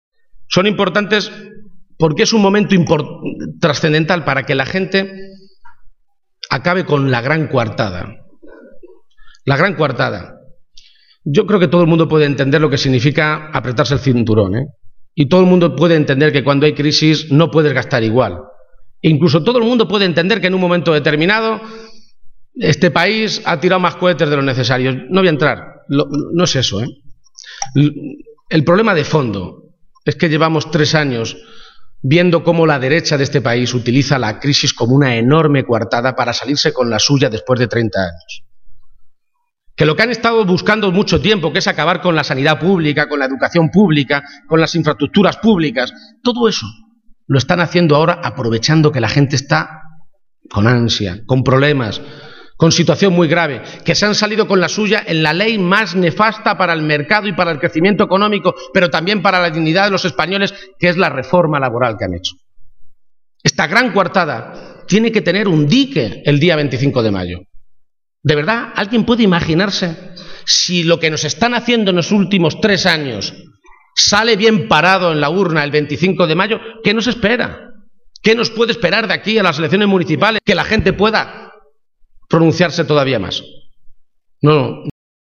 En ese acto público, con el Teatro Victoria abarrotado, Page ha dado toda una serie de argumentos por los que asegurar que una victoria del PSOE sobre el PP el domingo es importante.